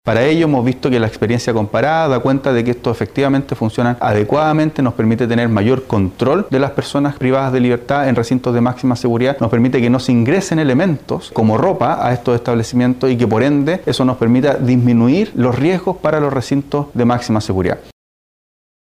Una cifra cercana al orden de los $10 mil millones costaría implementar esta idea, aunque el ministro Gajardo aseveró que, de esta forma, Gendarmería contará con un mayor control al interior de los penales de Chile.